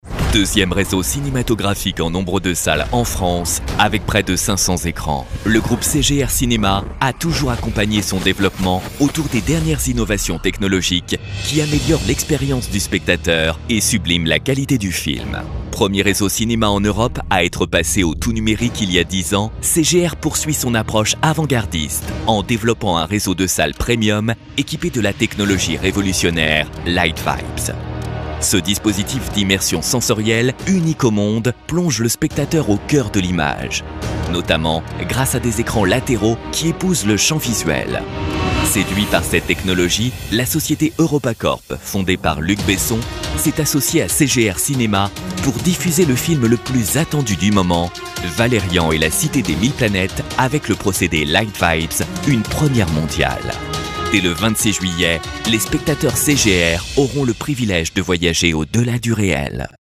ICE BY CGR cinéma, événement - Comédien voix off
Genre : voix off.